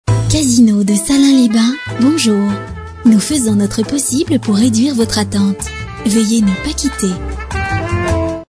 Corporate , POP